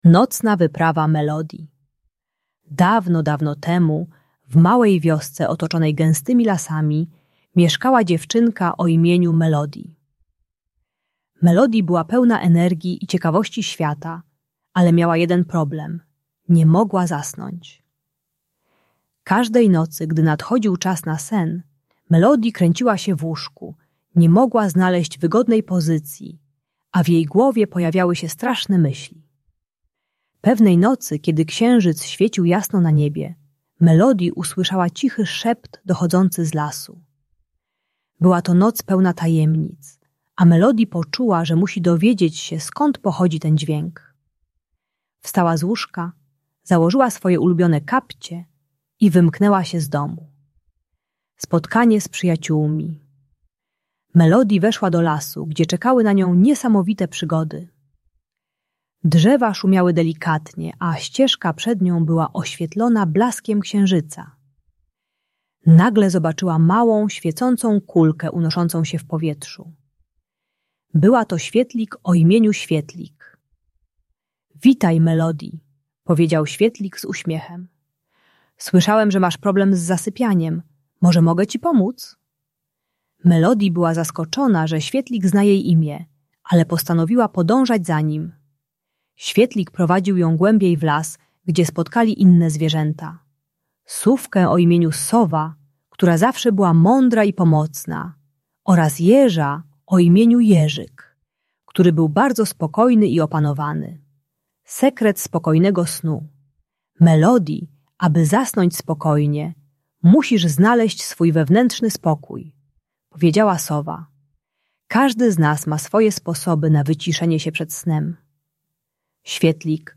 Bajka pomagająca zasnąć dla dzieci 4-6 lat, które kręcą się w łóżku i nie mogą zasnąć. Ta bajka dla dziecka które ma problemy z zasypianiem uczy techniki wizualizacji ciepłego światła oraz głębokiego oddychania. Audiobajka usypiająca z rytuałem relaksacyjnym przed snem.